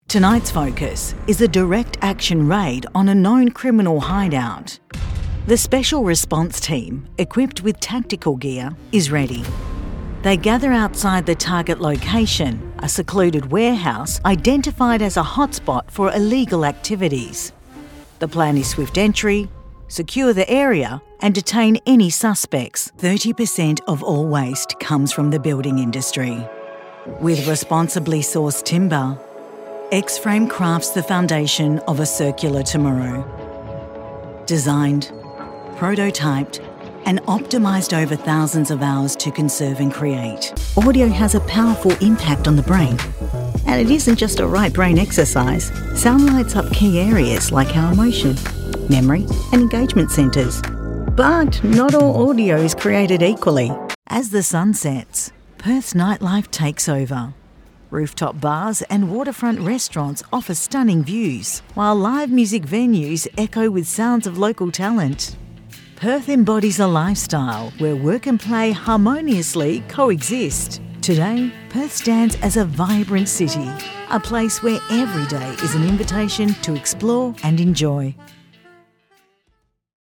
English (Australian)
Narration